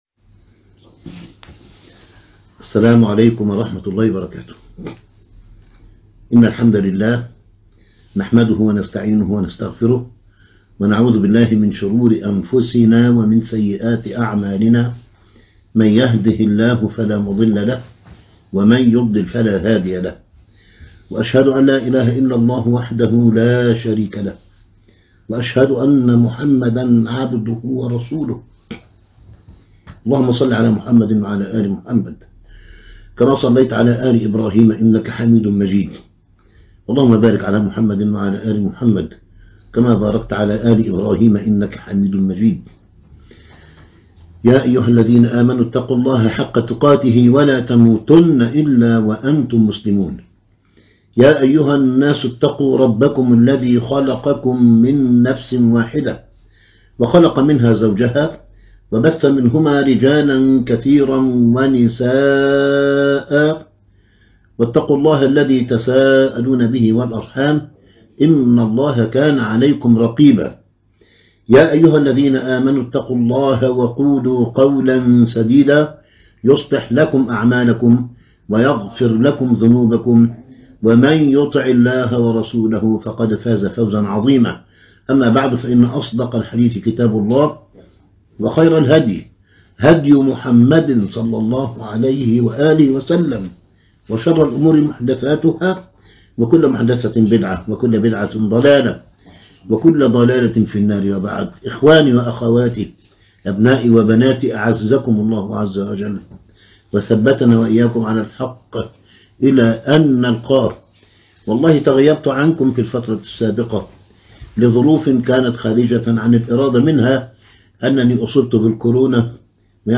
تصحيح مفاهيم - الدرس -1- 2022